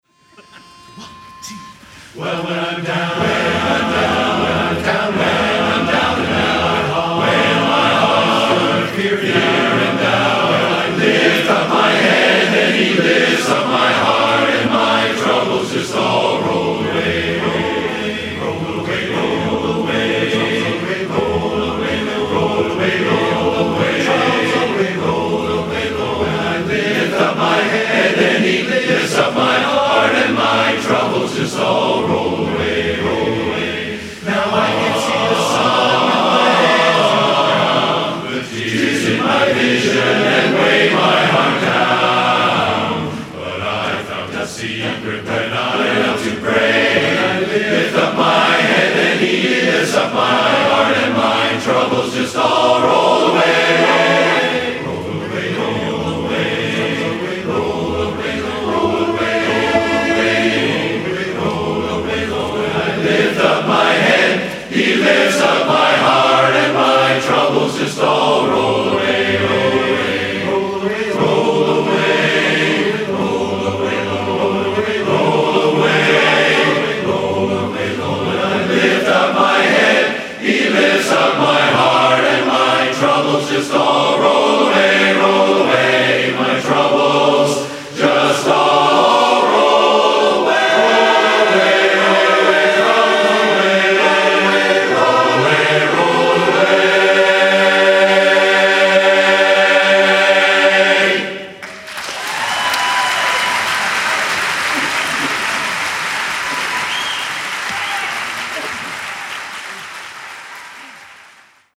a capella voices raised—
united in song